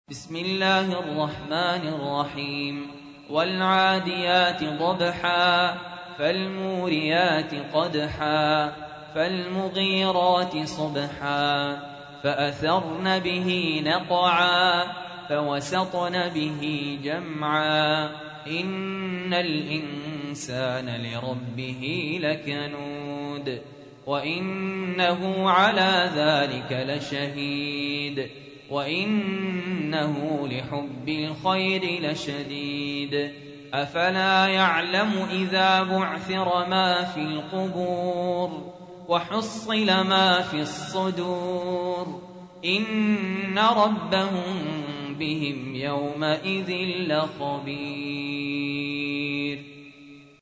تحميل : 100. سورة العاديات / القارئ سهل ياسين / القرآن الكريم / موقع يا حسين